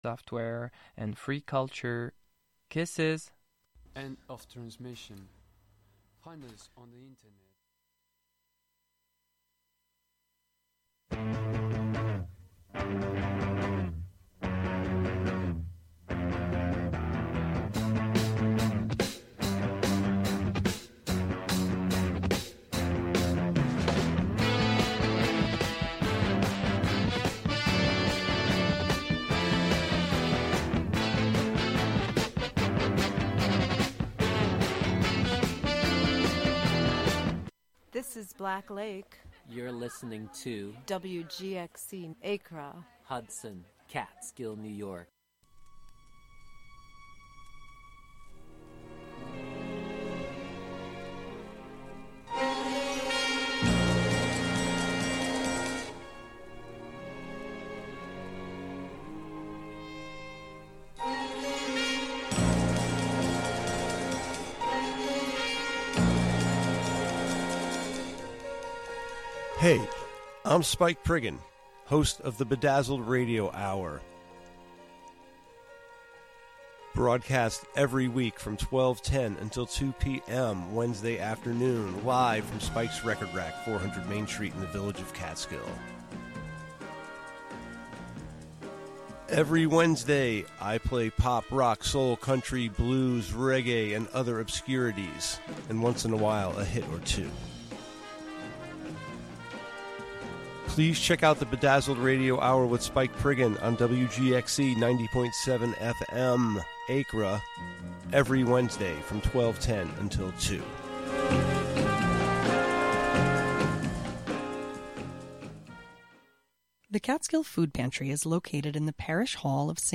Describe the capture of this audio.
In-studio / pedestrian interviews, local event listings, call-ins, live music, and other chance connections will be sought on air.